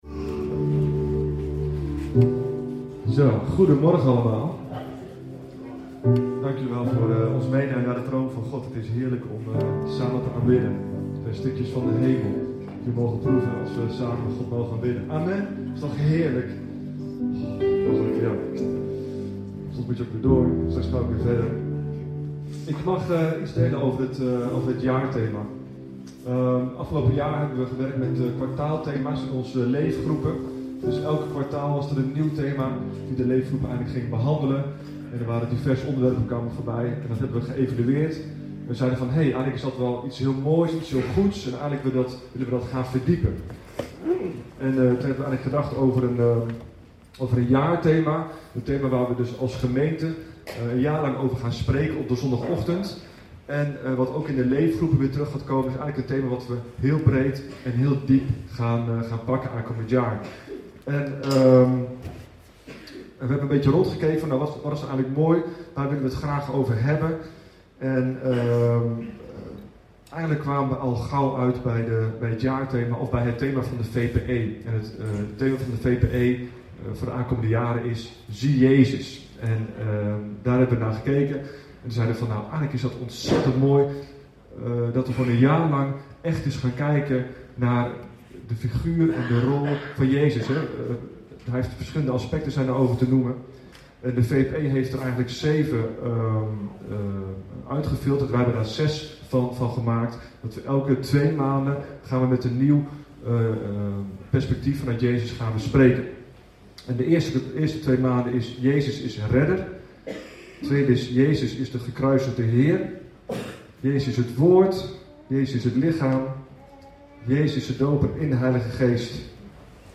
Getuigenissendienst 47:47